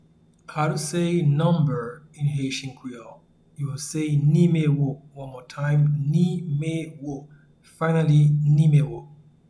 Pronunciation and Transcript:
Number-in-Haitian-Creole-Nimewo.mp3